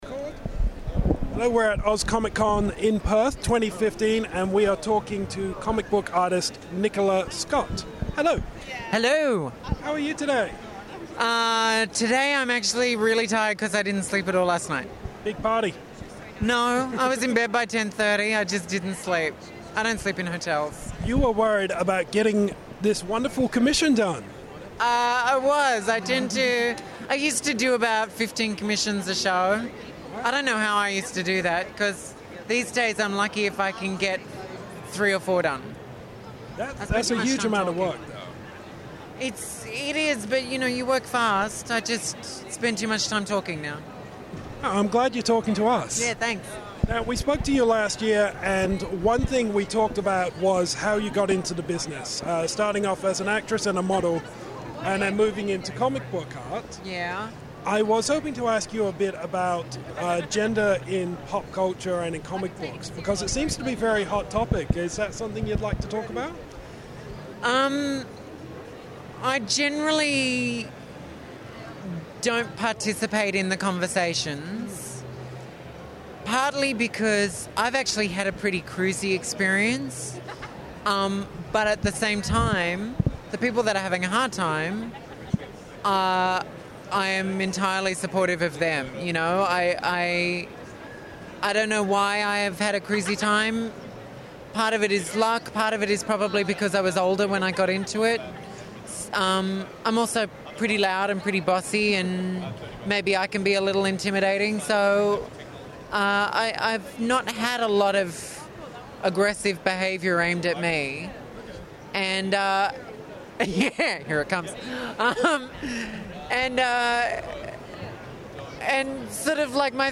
Exclusive Interview with Nicola Scott!
Audio: Nicola Scott Oz ComicCon House of Geekery